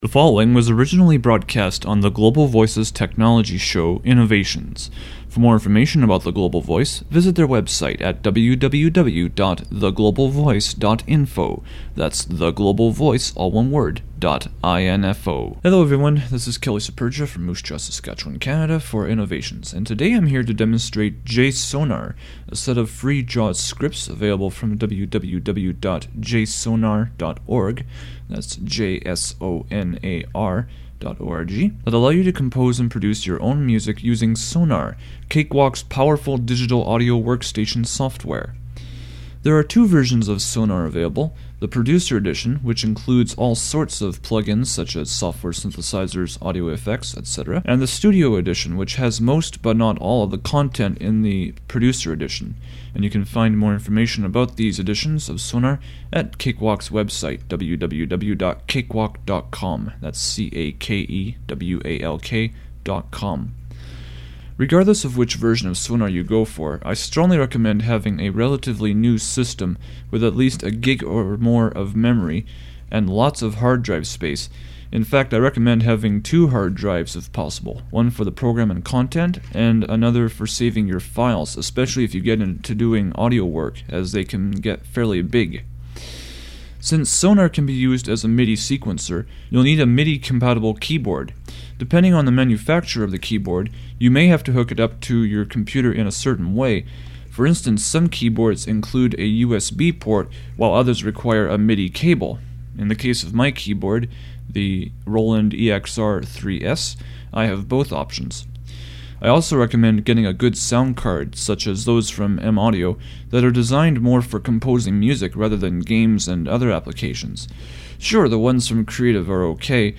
Since the show was originally broadcast in mono at the time, most of the reviews below are in mono unless otherwise indicated.